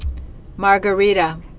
(märgə-rētə)